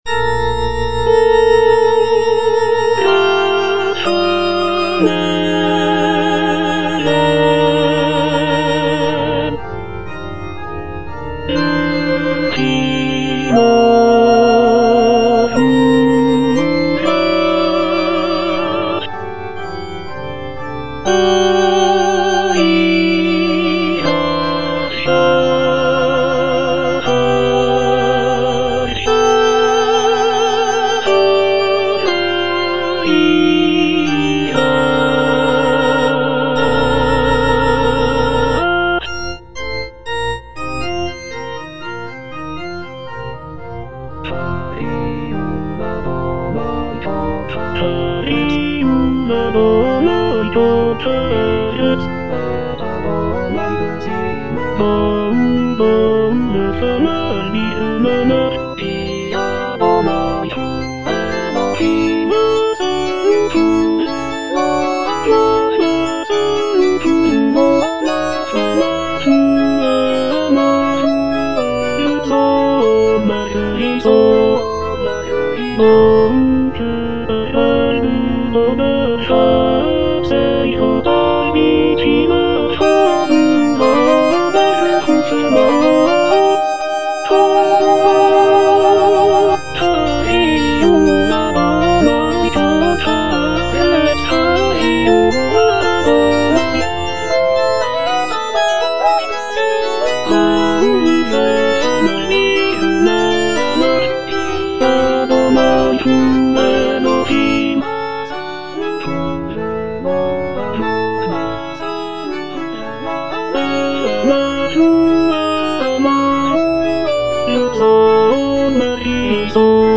(tenor I) (Emphasised voice and other voices)